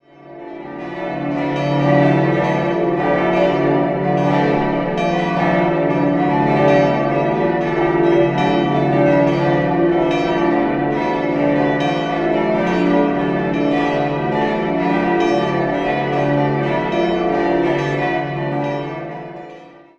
6-stimmiges ausgefülltes und erweitertes Wachet-auf-Geläute: d'-e'-fis'-a'-h'-d''
Die Glocken wurden 1958 von der Gießerei Kurtz in Stuttgart gegossen - einem in Bayern nur relativ wenig vertretenen Gießer.
Die fünf großen Glocken hängen im großen Turm, während die kleine Glocke im offenen Giebeltürmchen läutet und daher dem Geläute an Hochfesten eine unüberhörbare Krone aufsetzt.